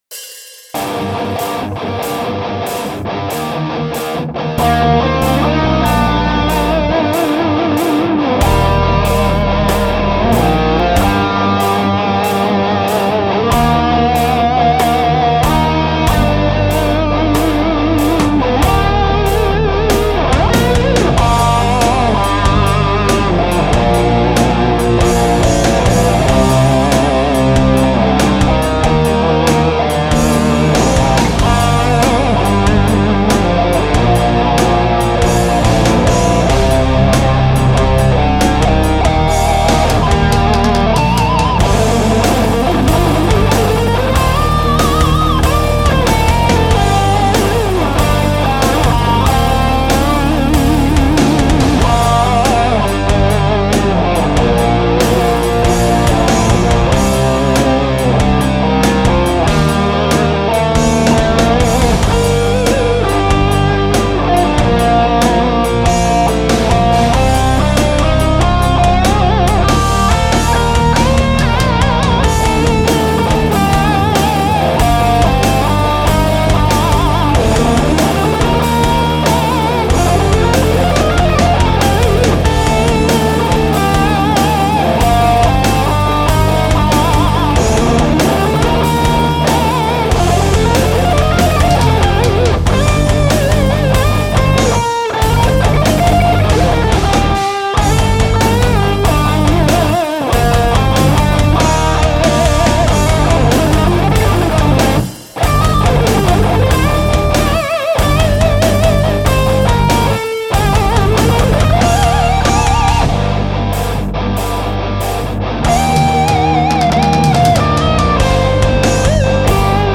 HIRE – guitar project
Tento ujetý instrumentální kytarový projekt se dostal do nové fáze. Cvok už dostal konečně rozum a tak tyto nové skladby od čísla 40 jsou jednodušší a srozumitelnější i normálnímu okolí.